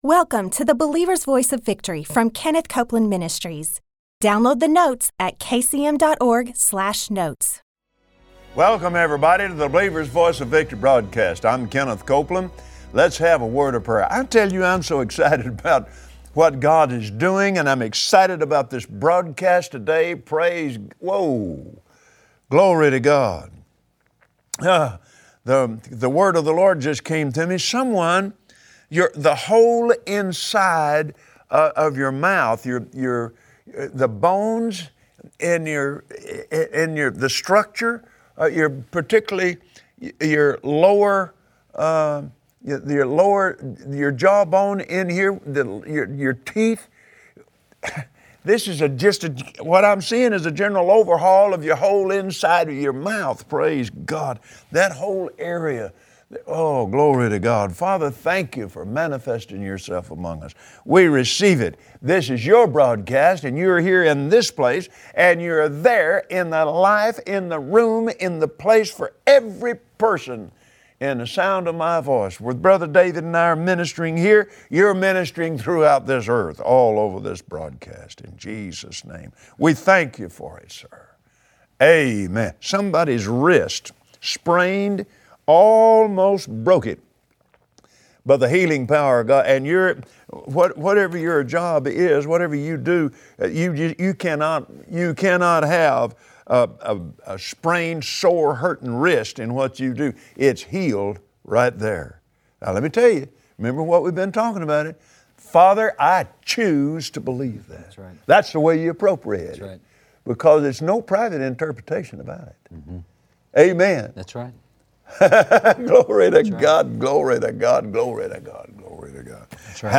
Join Kenneth Copeland and his guest, David Barton, as they discuss God’s grace and His place for every member of the body of Christ. Follow God and learn to recognize His grace for you in the end-time awakening of your nation.